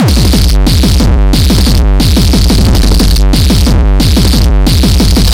Vinyldrums02
描述：脏，黑胶鼓，磁带鼓
Tag: 85 bpm Hip Hop Loops Drum Loops 1.90 MB wav Key : Unknown